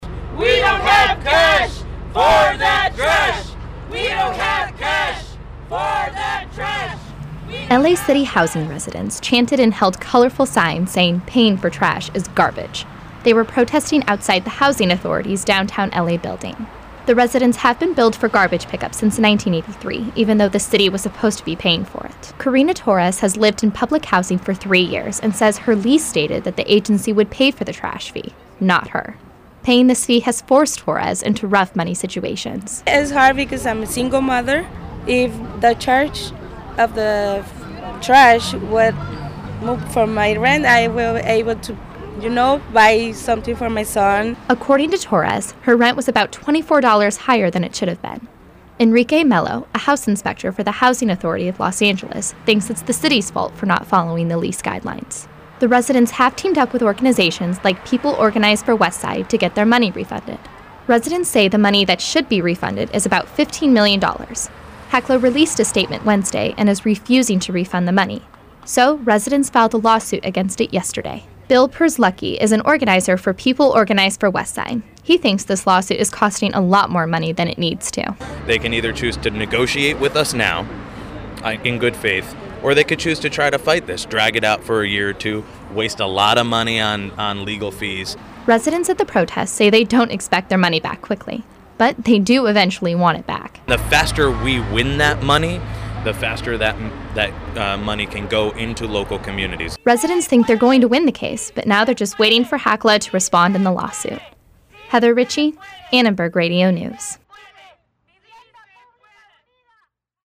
L.A. city housing residents chanted and held colorful signs saying "Paying for trash is garbage". They were protesting outside the Housing Authority's downtown L.A. building.